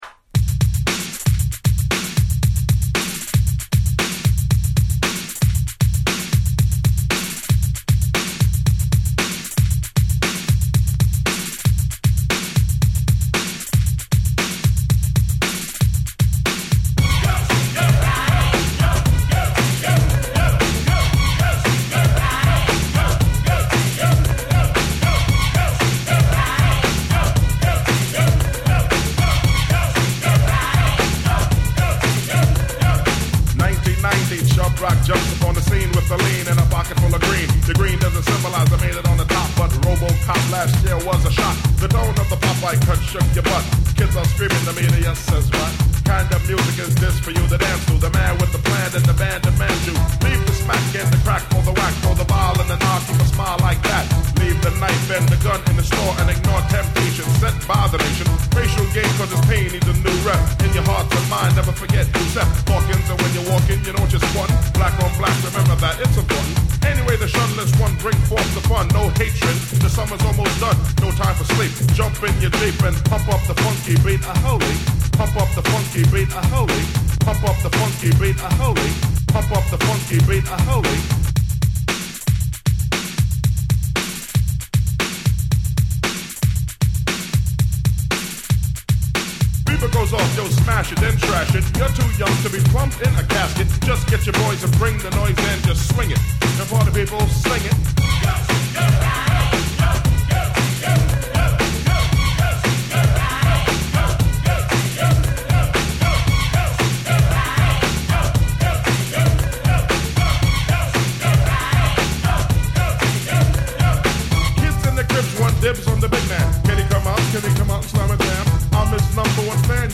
【Media】Vinyl 12'' Single
ダンサーを踊らせる為の必需品